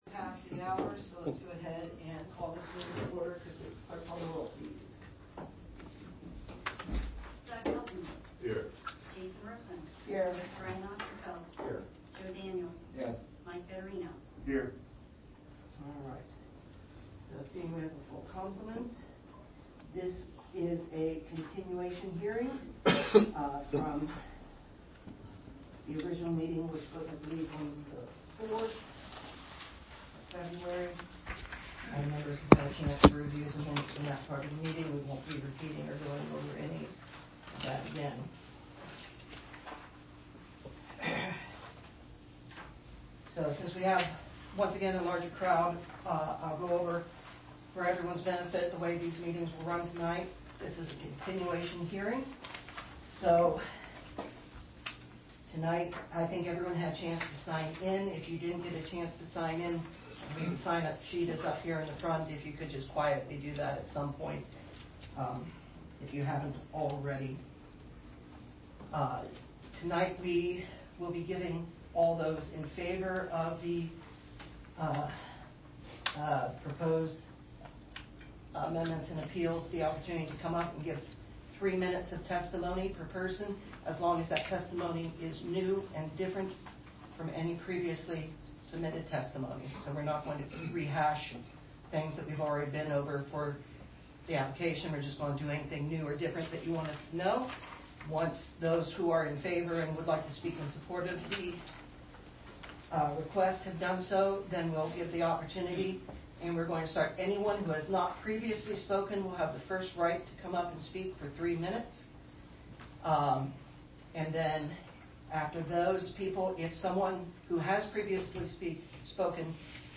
Audio of meeting included below
Zoning-Variance-Hearing_2.mp3